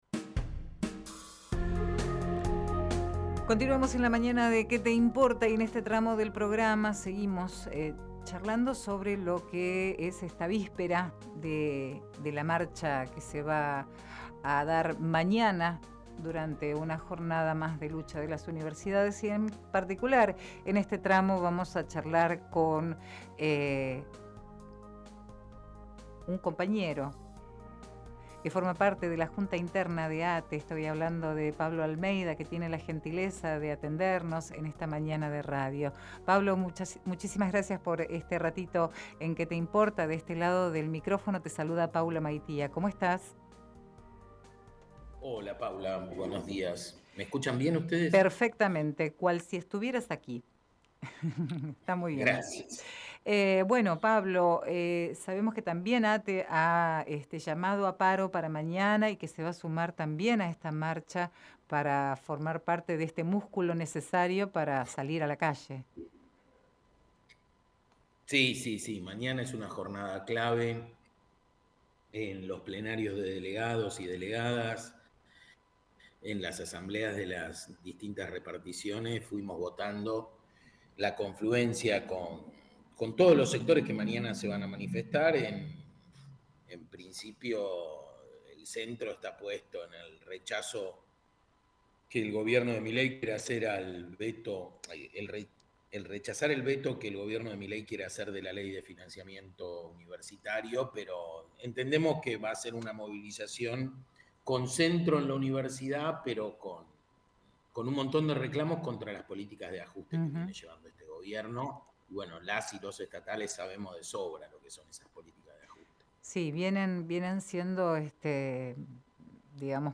Compartimos la entrevista